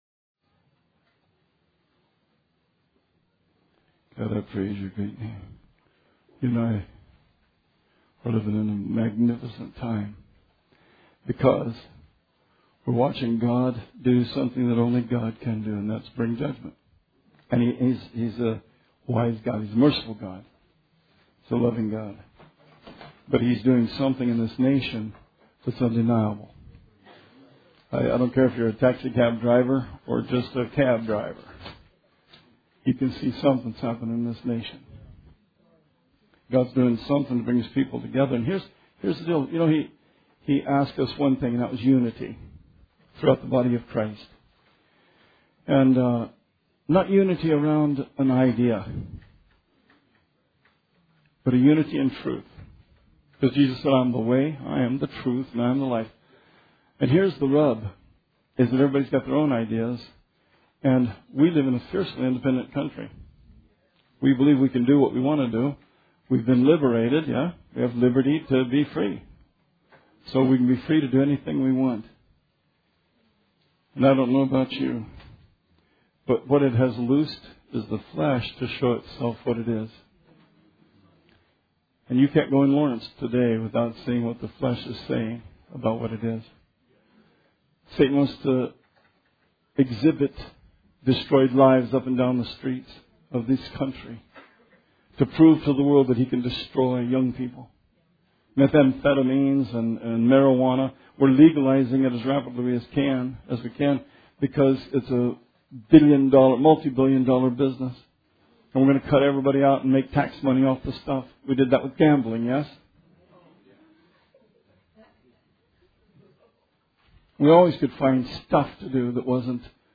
Sermon 8/14/16